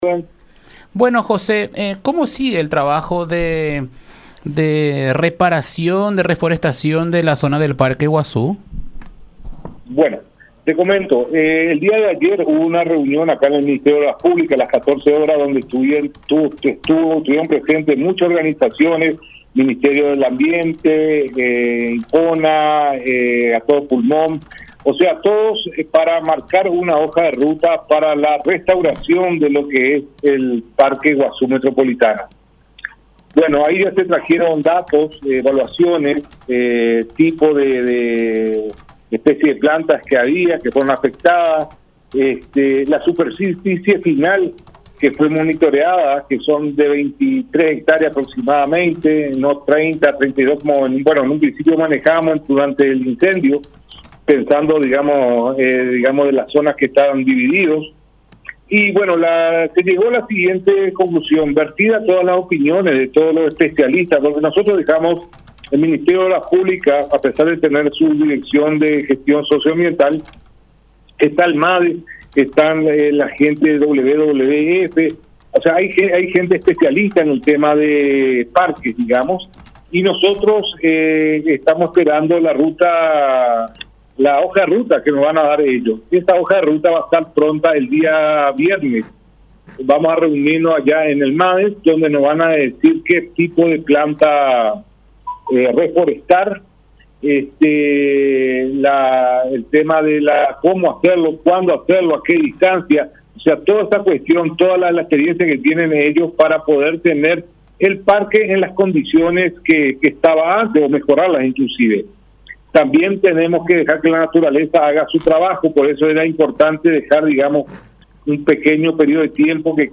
“Esto es con el objetivo de trabajar de manera unida en la reforestación de la zona del Parque Guasu”, expuso José Kronawetter, director de obras del Ministerio de Obras Públicas y Comunicaciones (MOPC), en diálogo con La Unión.